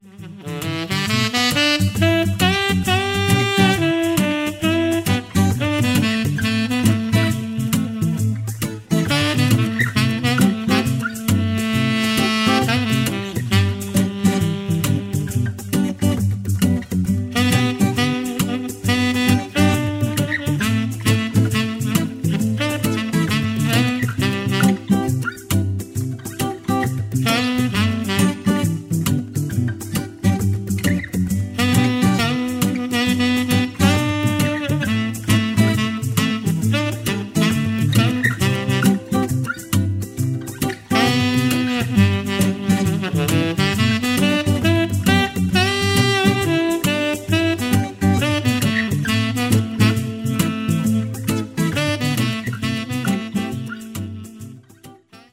Duo mit Saxophon und Gitarre